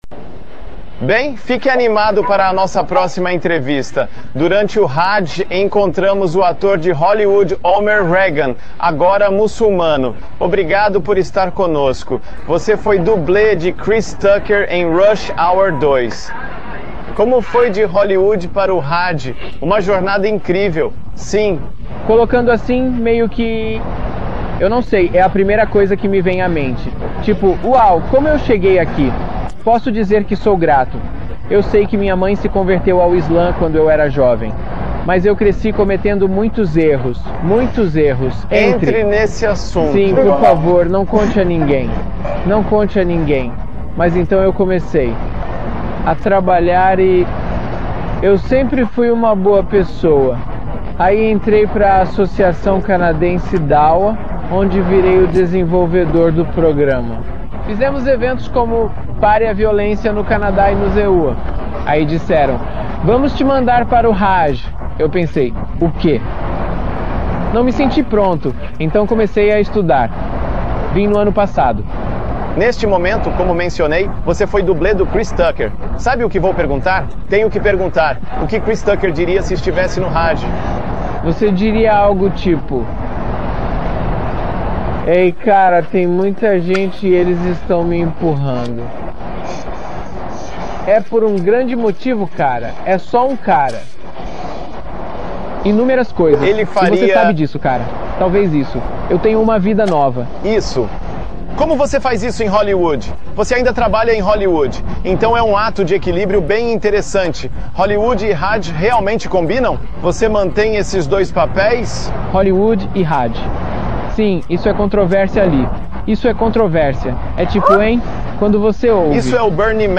é entrevistado pelo canal internacional de televisão Al-Jazeera enquanto realiza o quinto pilar do Islã.